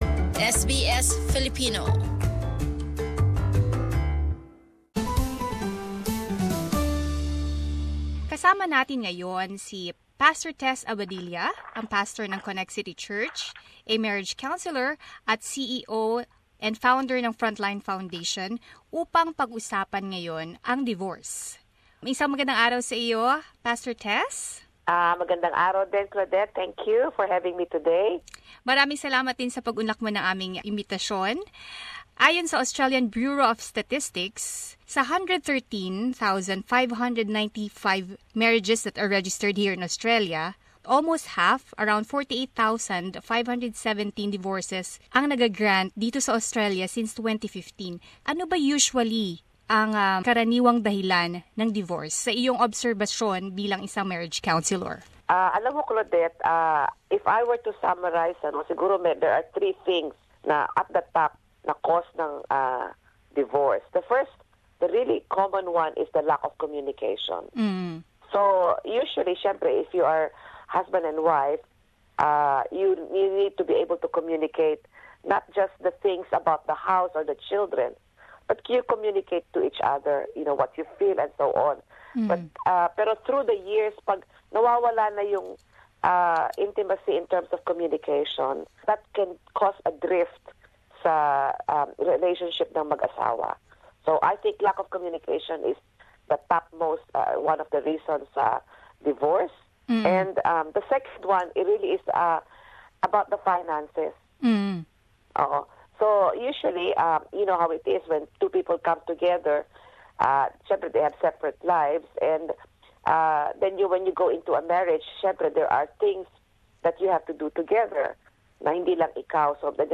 With the rate of divorce gradually increasing over the past years, how do we keep the marriage intact and strong and what are the common reasons of divorce. Let's listen to this practical talk given by a marriage counselor.